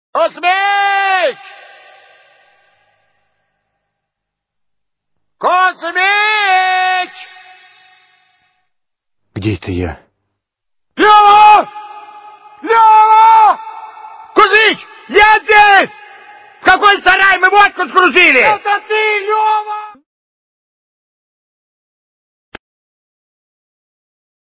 Отрывок диалога Кузмича с Левой про водку(из к/ф Особенности национальной рыбалки)